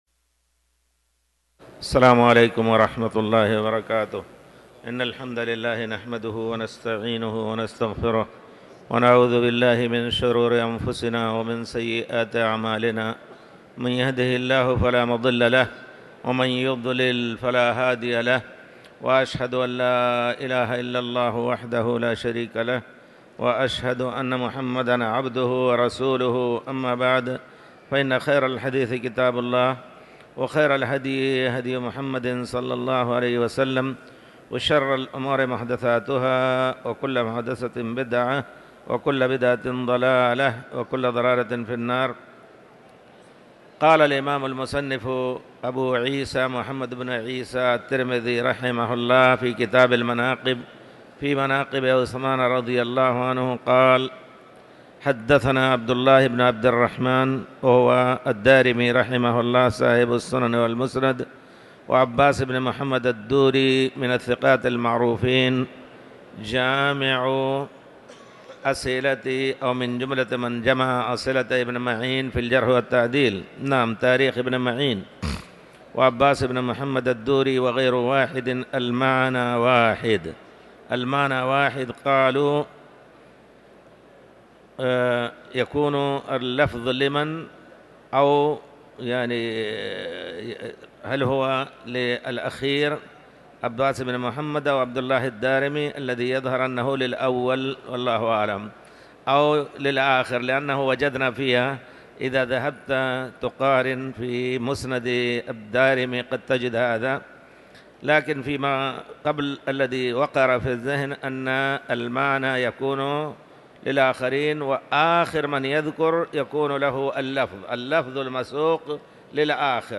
تاريخ النشر ١٣ رجب ١٤٤٠ هـ المكان: المسجد الحرام الشيخ